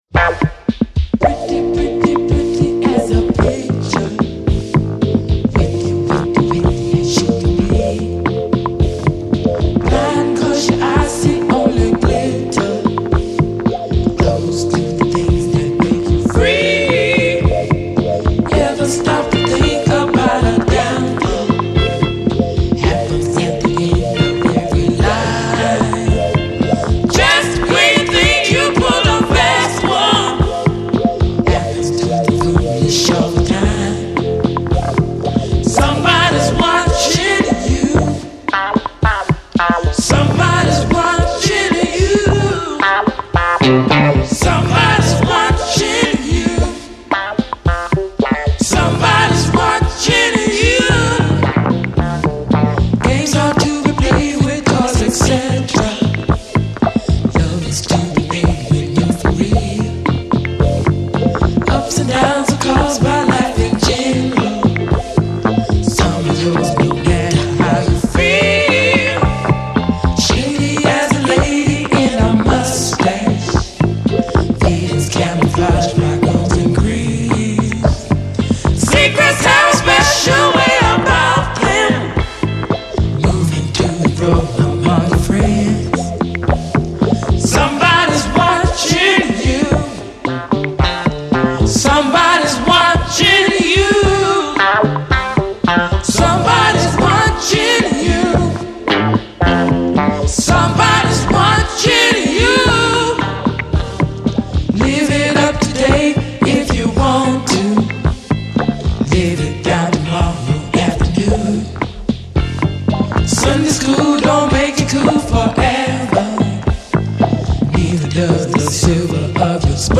darker and moodier than the original